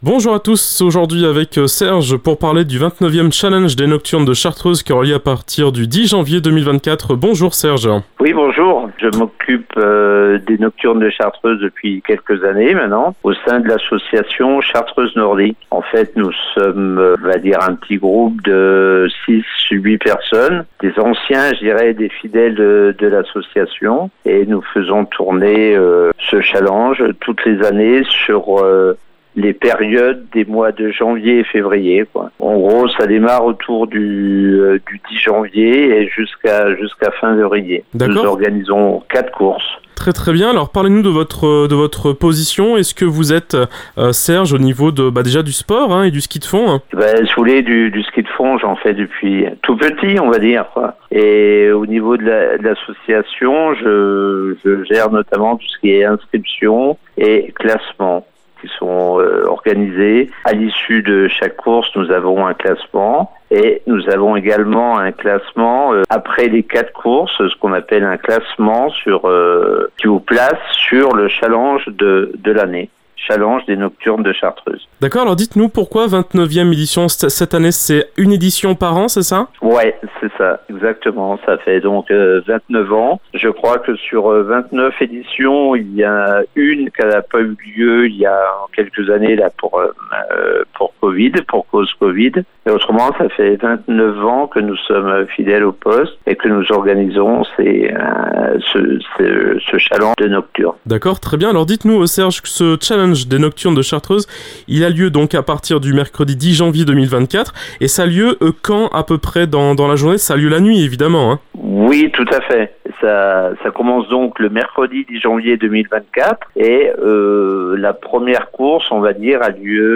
L’invité du jour
Portrait de la semaine